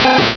sovereignx/sound/direct_sound_samples/cries/rattata.aif at master
rattata.aif